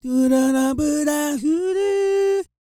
E-CROON 3017.wav